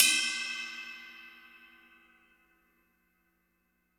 Crashes & Cymbals
Str_Cym2.wav